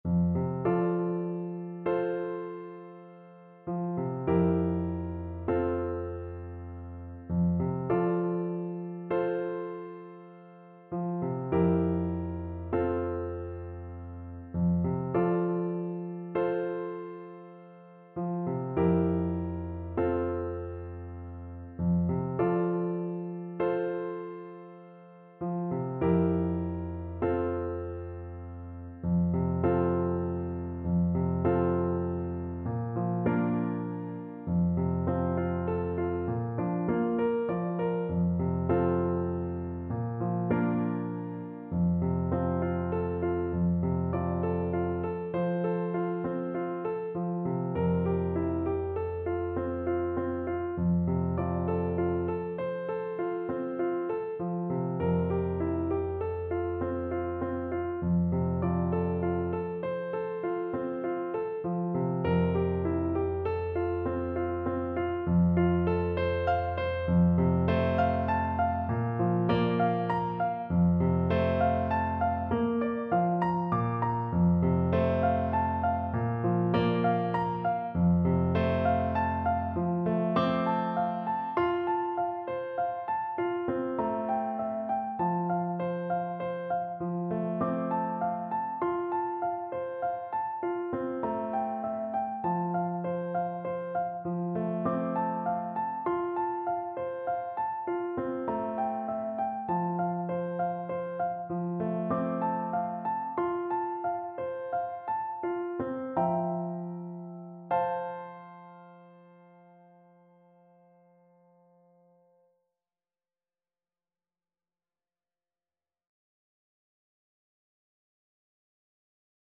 Traditional Trad. Abiyoyo (South African Lullaby) Clarinet version
Clarinet
Traditional Music of unknown author.
Gently .=c.48
F major (Sounding Pitch) G major (Clarinet in Bb) (View more F major Music for Clarinet )
6/8 (View more 6/8 Music)
D5-D6
world (View more world Clarinet Music)